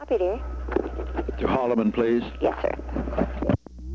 Location: White House Telephone
The President talked with the White House operator.